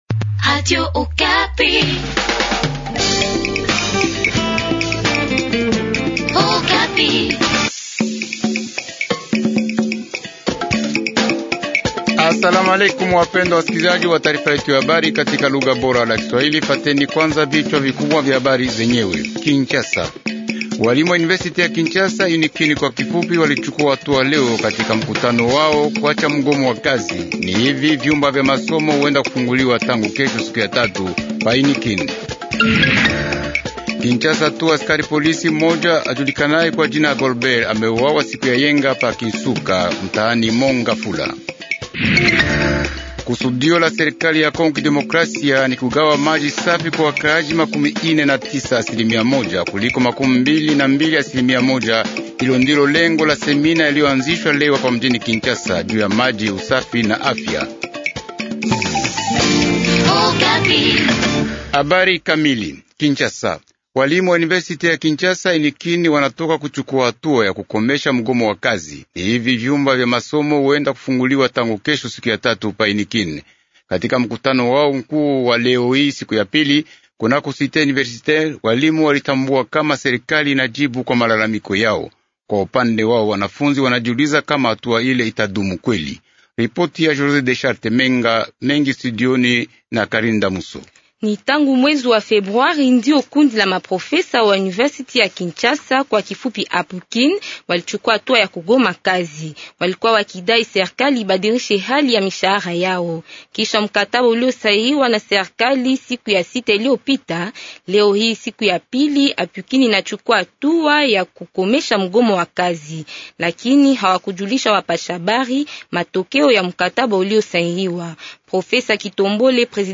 Journal swahili du soir